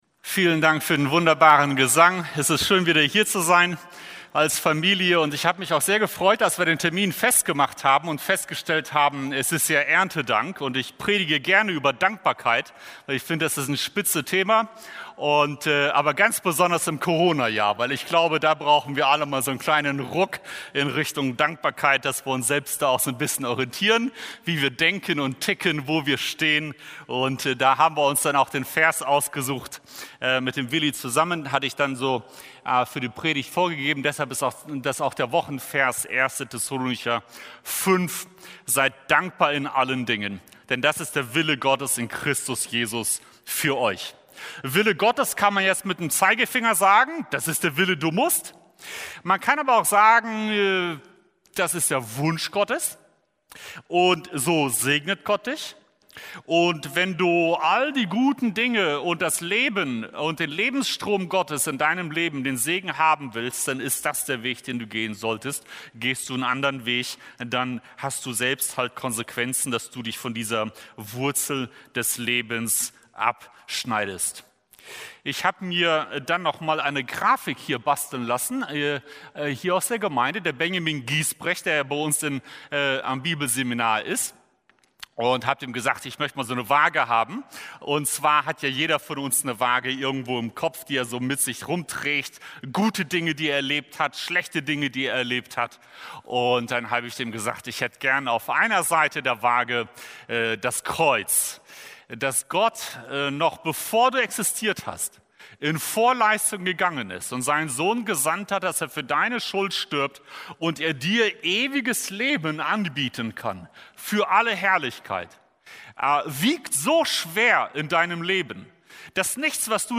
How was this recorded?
Erntedank 2020- Evangeliums-Christengemeinde